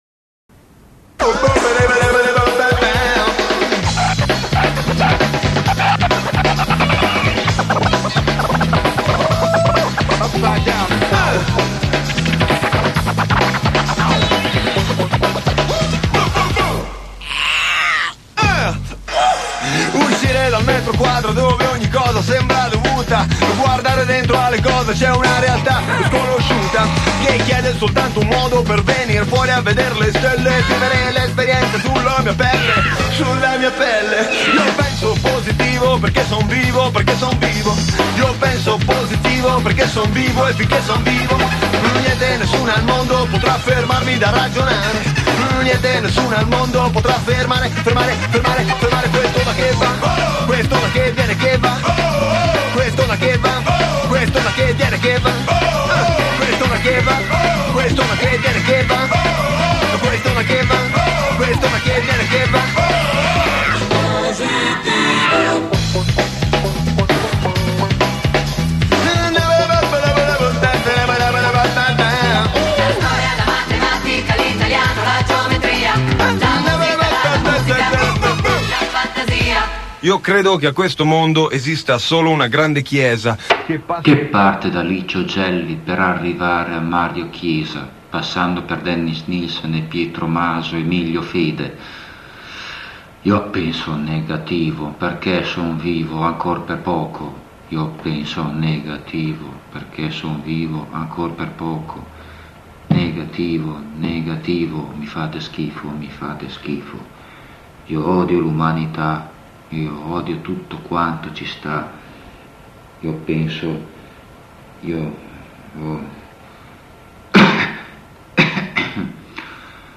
sono qui raccolti alcuni pezzi estratti dalle registrazioni su nastro dei primi anni '90.